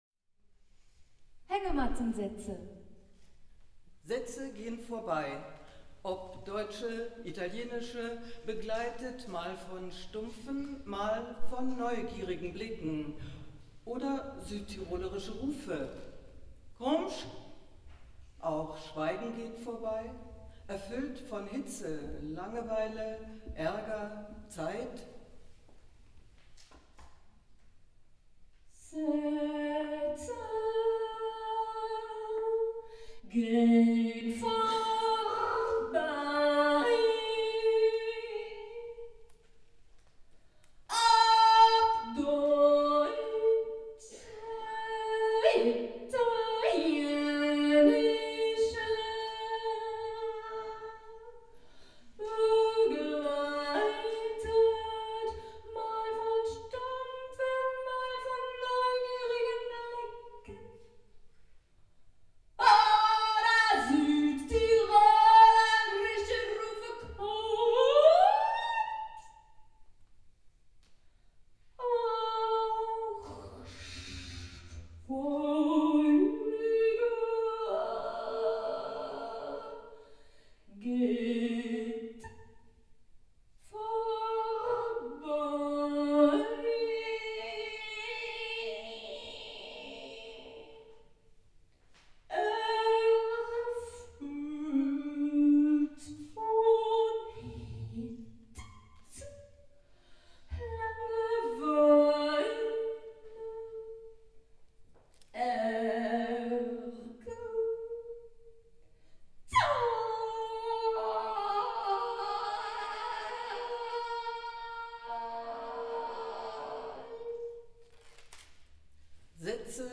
Hängemattensätze - Solo pder Duo-Performance (mit Sprecher/in)
Live in Freiburg 2012 (Ausschnitt)